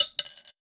weapon_ammo_drop_05.wav